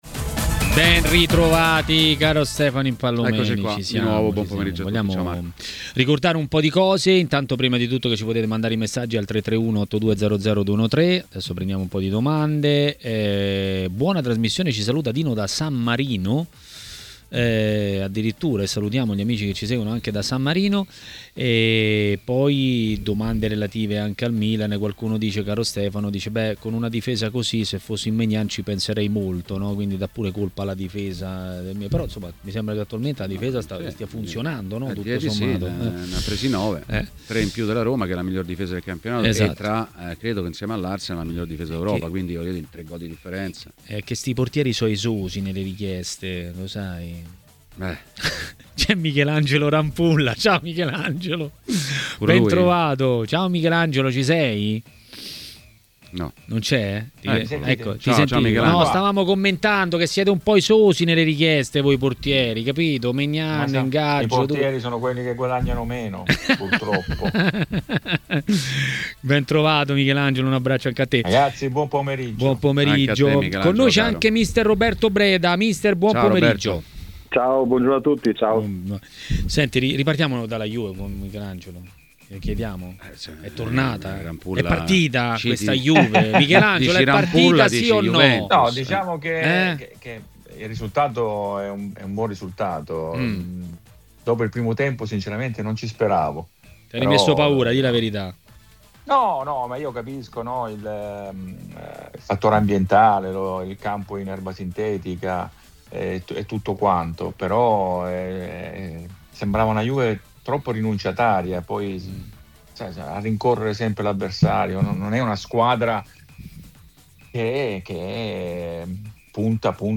A parlare dei temi del giorno a TMW Radio, durante Maracanà